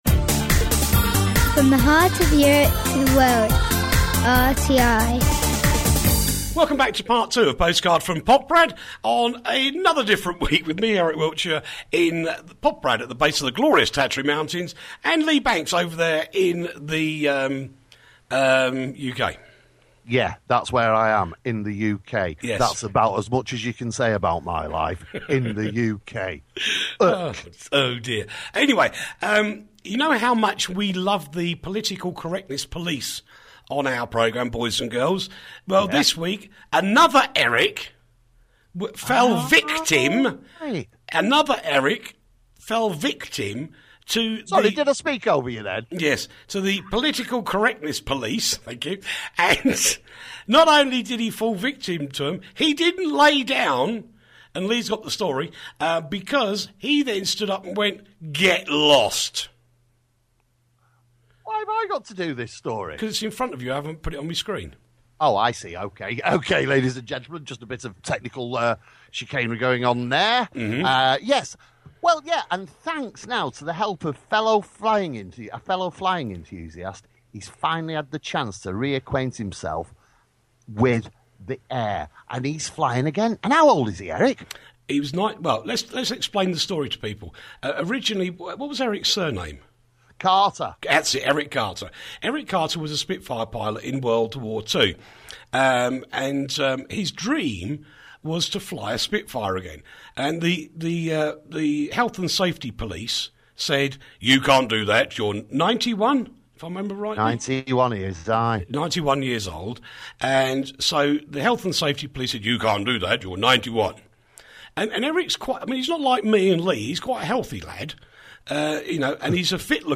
Postcard From Poprad the alternative news show from Radio Tatras International